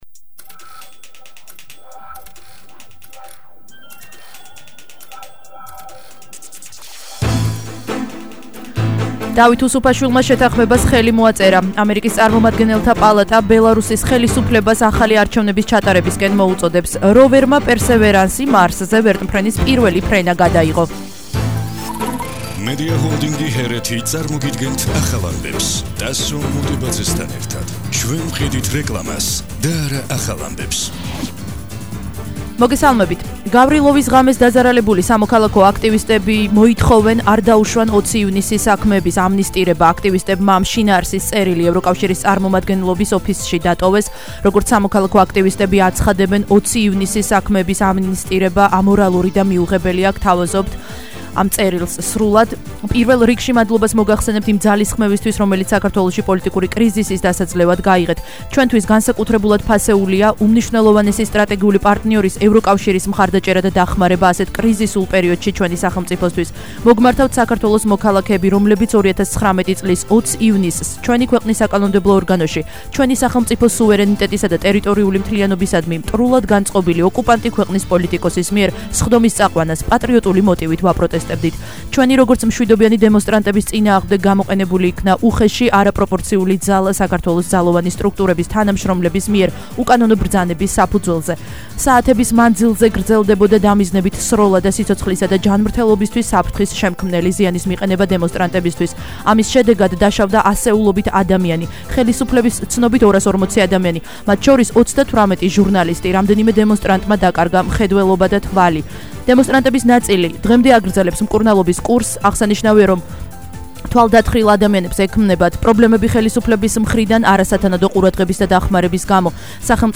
ახალი ამბები 17:00 საათზე –21/04/21 - HeretiFM